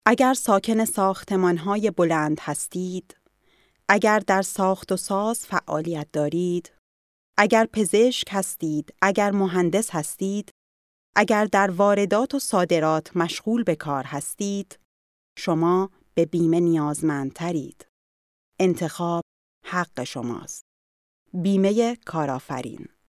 Sprechprobe: Industrie (Muttersprache):
02 Werbung für Rabeitsversicherung.mp3